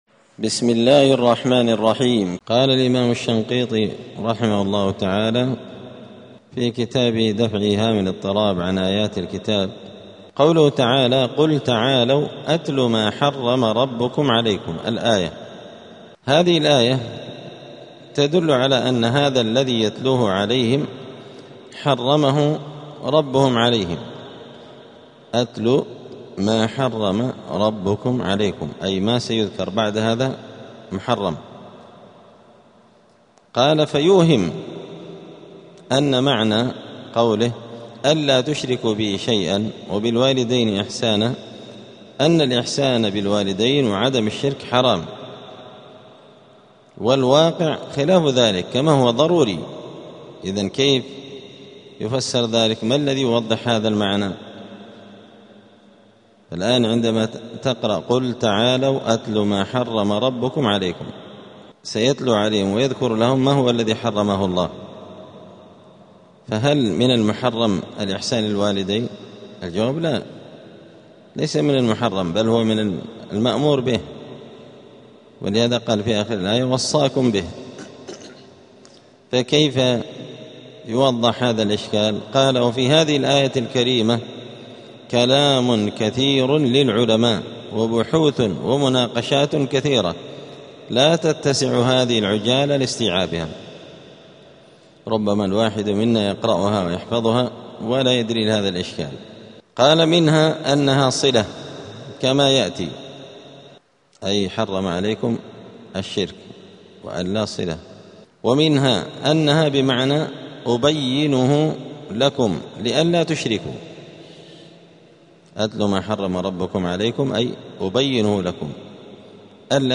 *الدرس الثاني والأربعون (42) {سورة الأنعام}.*